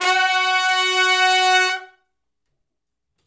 Index of /90_sSampleCDs/Sonic Foundry (Sony Creative Software) - Crimson Blue and Fabulous Horncraft 4 RnB/Horncraft for R&B/Sections/058 Hi Medium Long Note
058 Hi Medium Long Note (F#) uni.wav